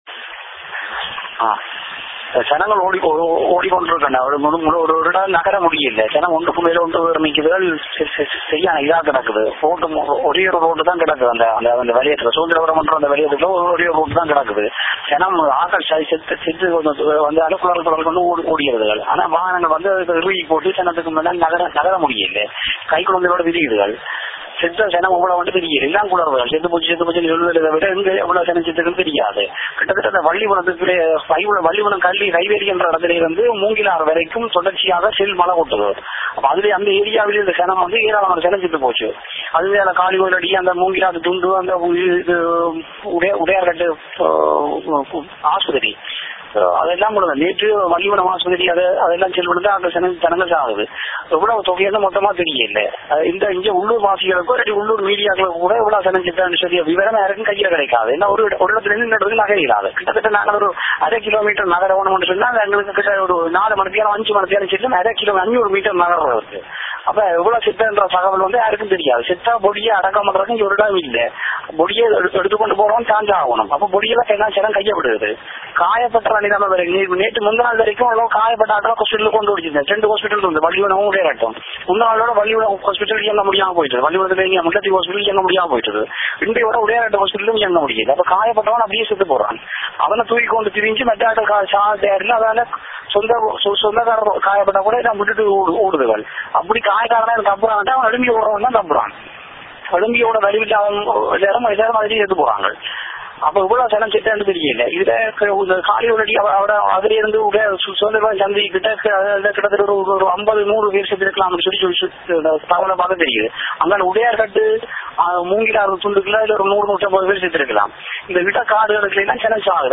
[EYEWITNESS ACCOUNT: AUDIO]
TamilNet publishes direct eyewitness accounts from the street of Udaiyaarkaddu.